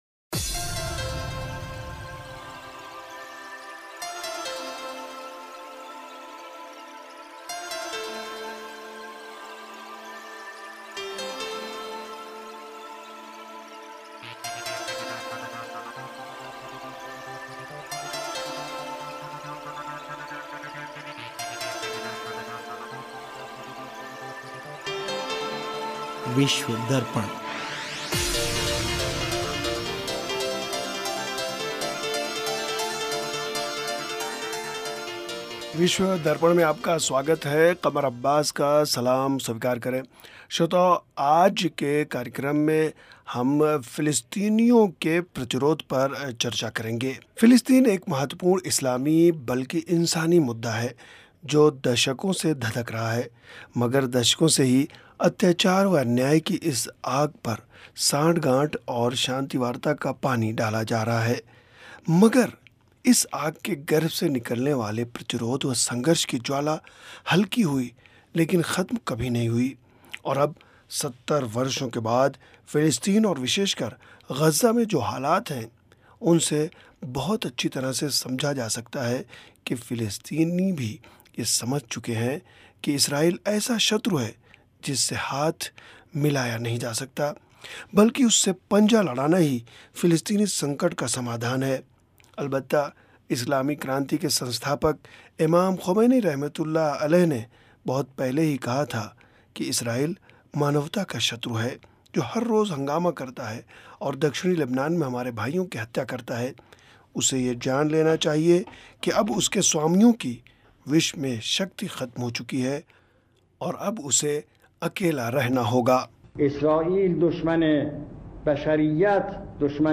फ़िलिस्तीनियों के प्रतिरोध पर चर्चा।